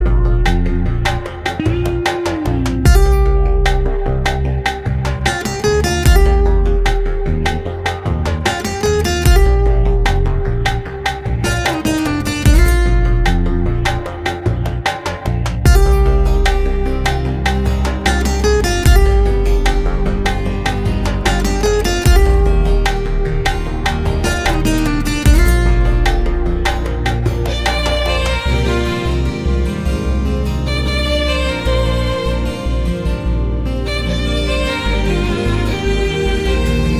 Categories: Instrumental Ringtones